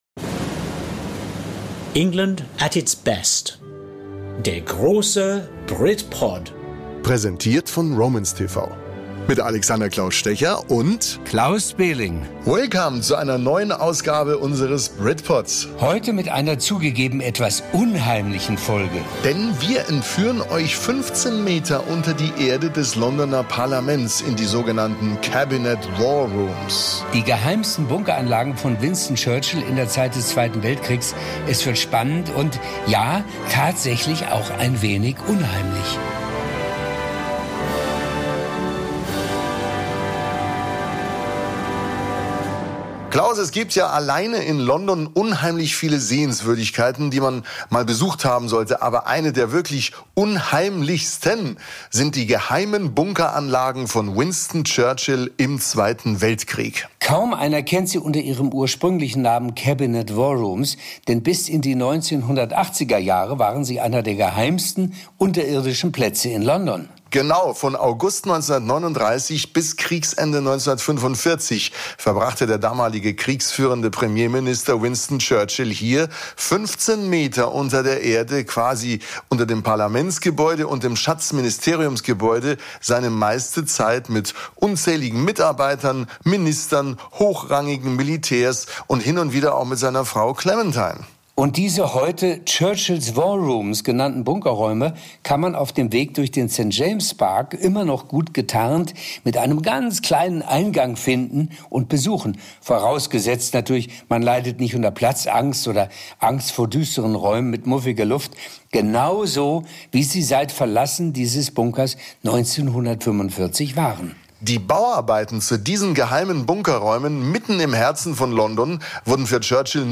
Original-Zitate von Churchills engsten Mitarbeitern und ein Stück britisch-europäische Geschichte zum Anhören - hier im BRITPOD - England at its Best.